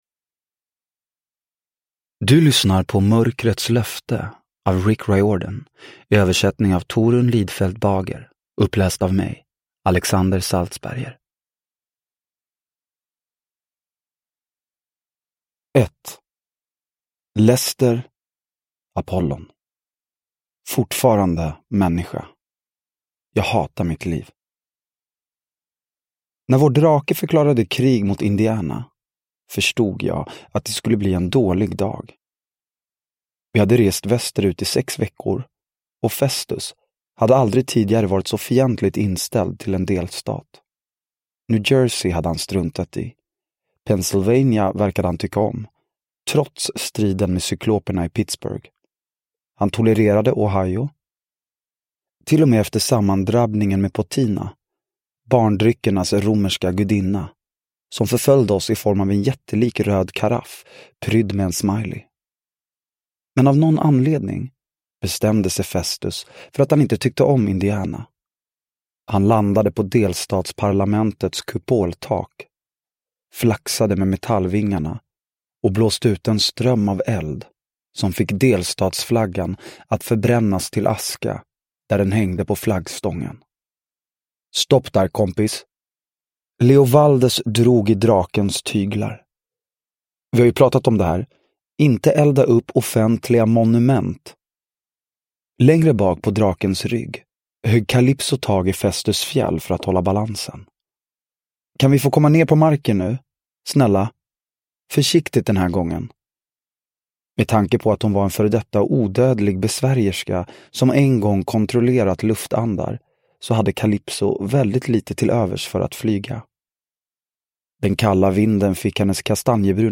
Mörkrets löfte – Ljudbok – Laddas ner